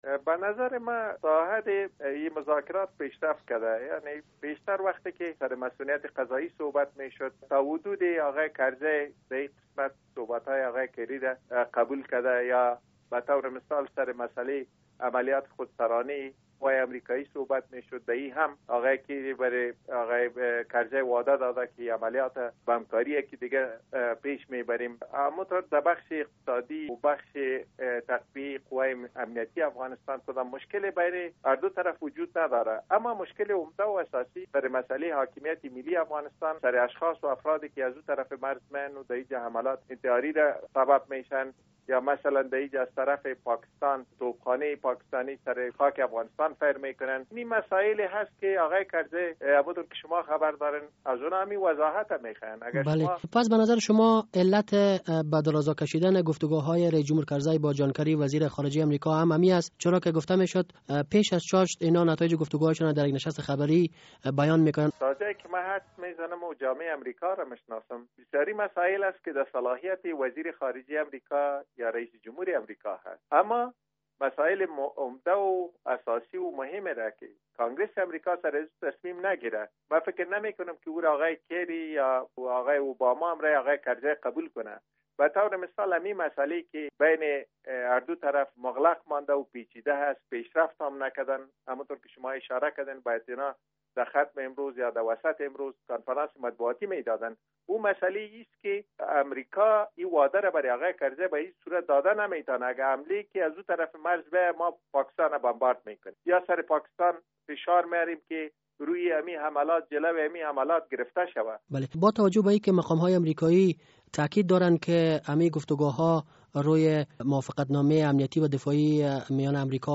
مصاحبه: پیشرفت ها در خصوص توافقنامهء امنیتی و دفاعی صورت گرفته است